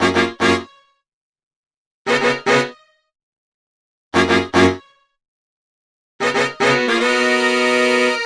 描述：4个无缝小节的高度摇摆的RB鼓+打击乐槽，中等速度，类似于1968年Barbara Acklin的灵魂唱片"Love Makes A Woman"中听到的。
标签： 116 bpm Soul Loops Drum Loops 1.40 MB wav Key : Unknown
声道立体声